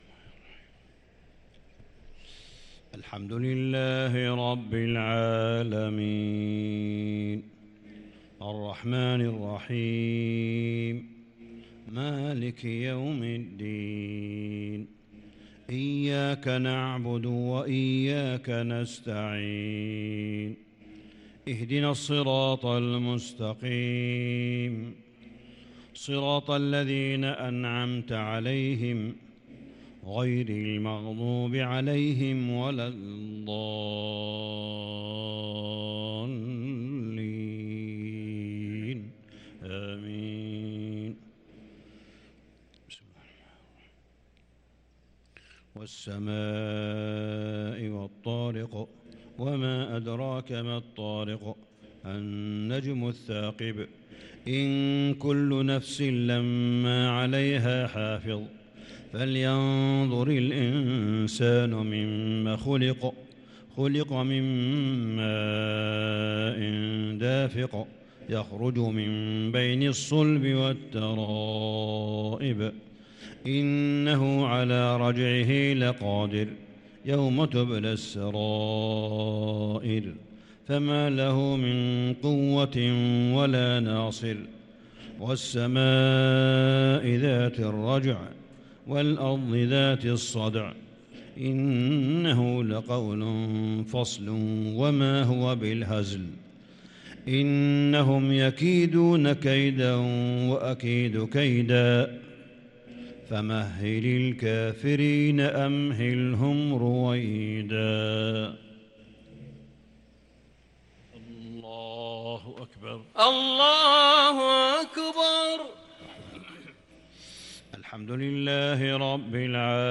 عشاء الجمعة 7-9-1443هـ سورتي الطارق و الشمس | Isha prayer surat at-Tariq & ash-Shams 8-4-2022 > 1443 🕋 > الفروض - تلاوات الحرمين